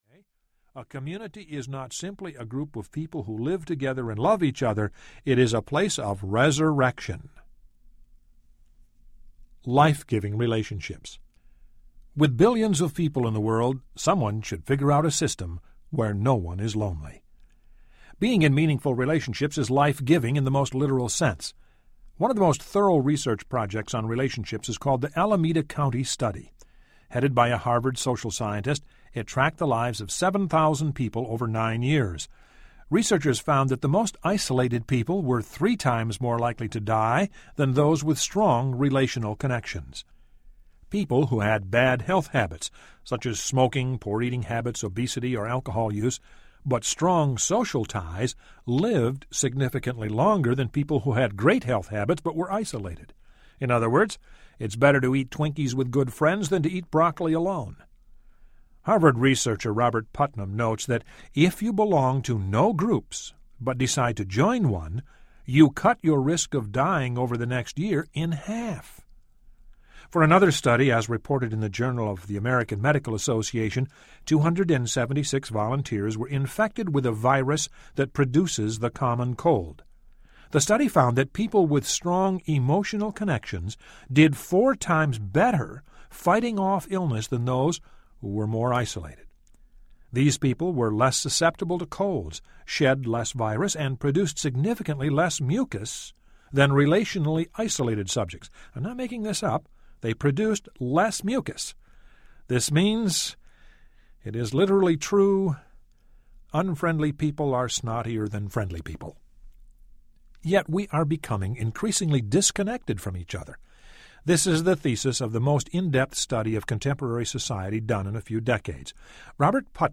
Everybody’s Normal Till You Get to Know Them Audiobook
Narrator
7.8 Hrs. – Unabridged